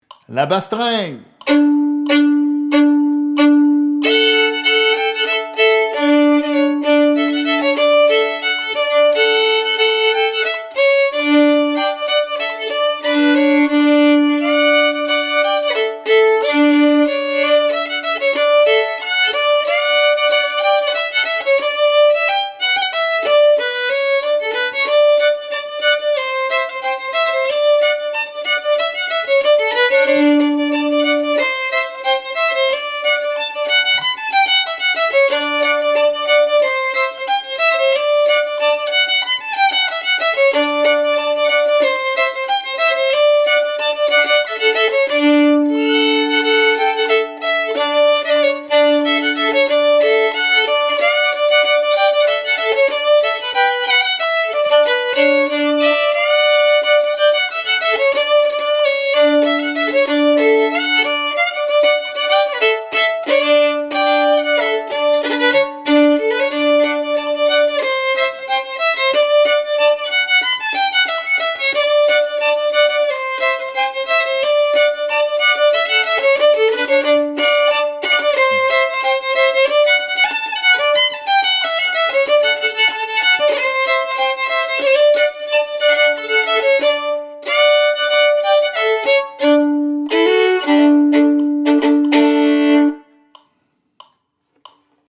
Key: D
Form:Reel
Region:Quebec